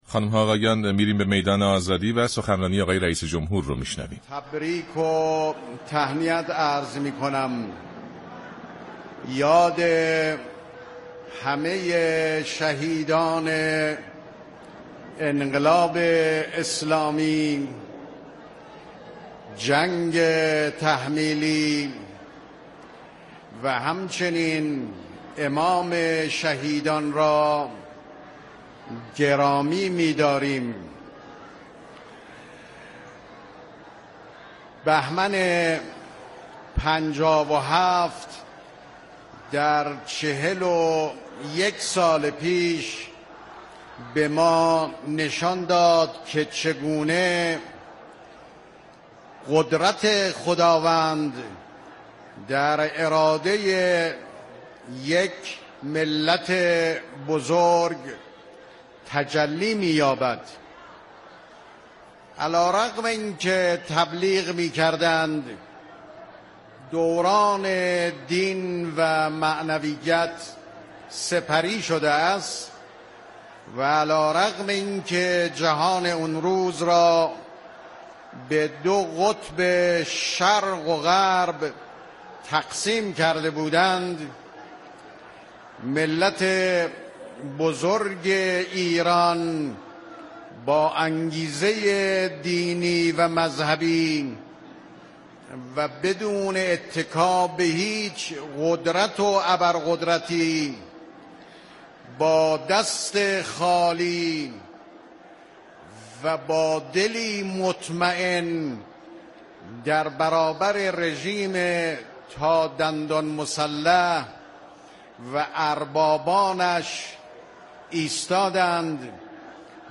حجت الاسلام والمسلمین حسن روحانی در مراسم راهپیمایی 22 بهمن گفت: روز تاریخی 22 بهمن را خدمت همه ملت بزرگوار ایران اسلامی و به محضر رهبر معظم انقلاب تبریك و تهنیت عرض می‌كنم و یاد همه شهیدان انقلاب اسلامی، جنگ تحمیلی و همچنین امام شهیدان را گرامی می‌داریم.